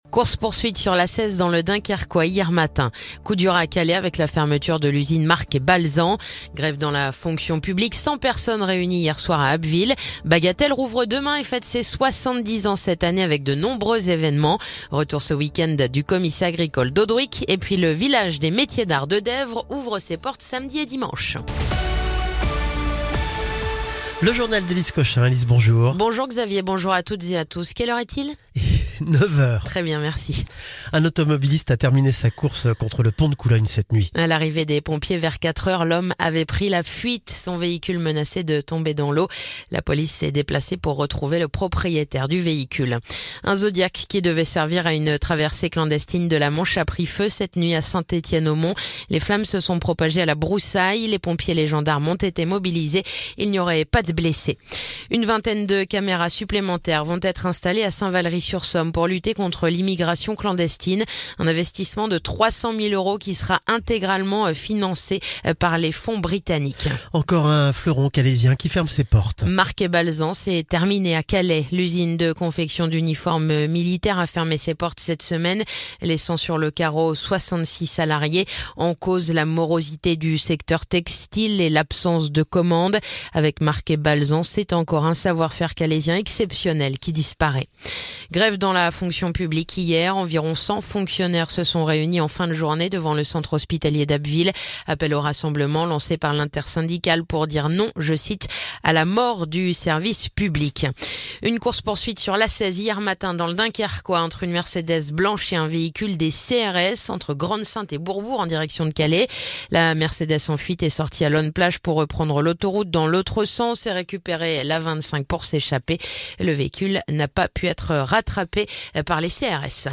Le journal du vendredi 4 avril